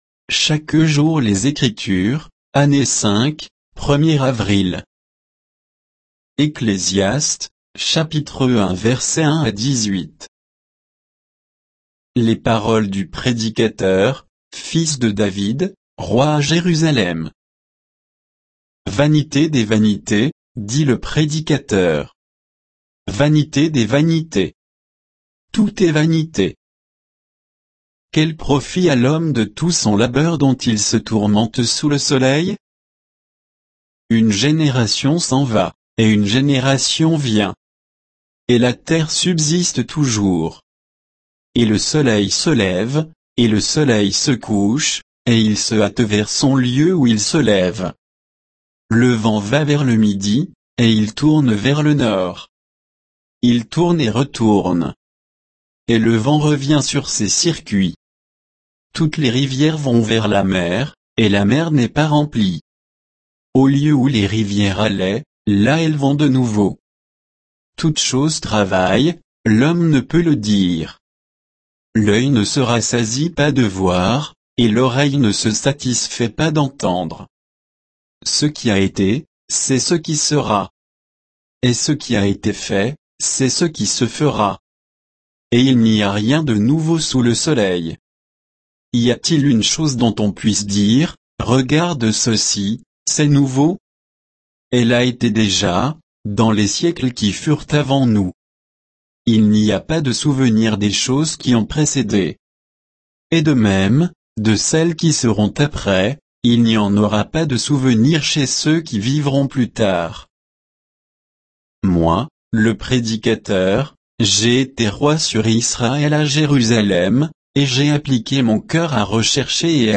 Méditation quoditienne de Chaque jour les Écritures sur Ecclésiaste 1